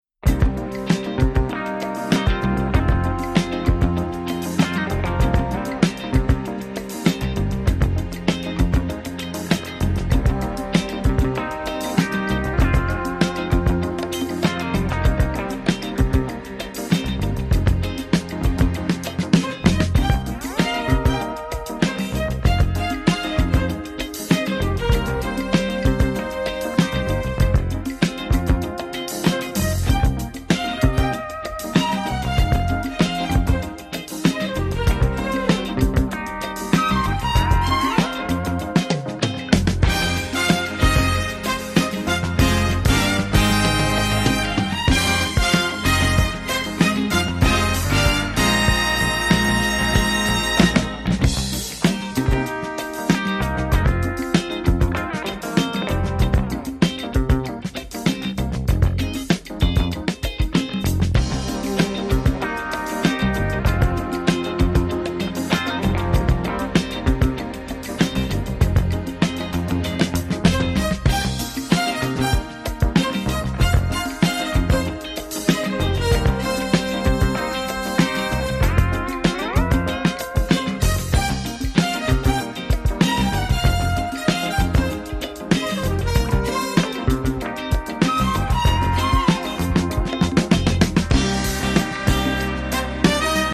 The funky, cool and slick AF